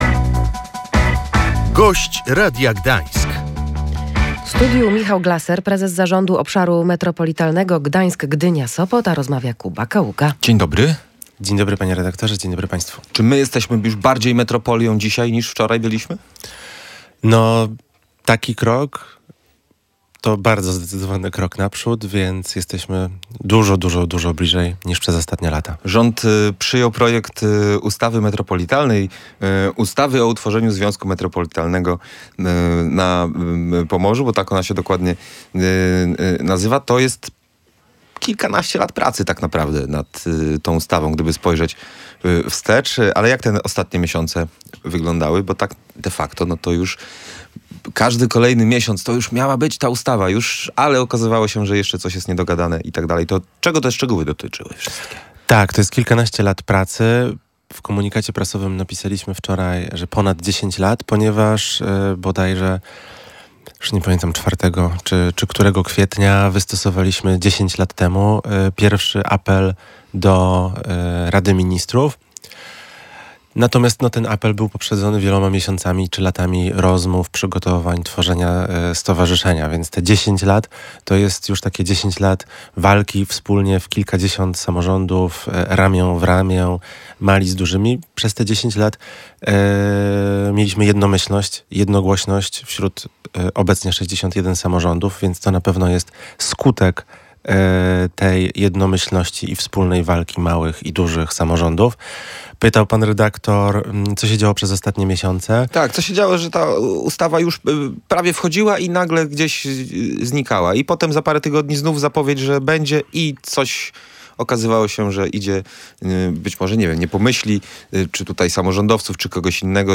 Liczymy na to, że od przyszłego roku część podatków będzie przeznaczona na to, żebyśmy my, jako wspólnota metropolitalna, mieli własny budżet na zarządzanie wspólnymi sprawami – mówił Gość Radia Gdańsk.